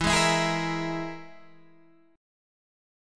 EMb5 chord {x 7 6 9 9 6} chord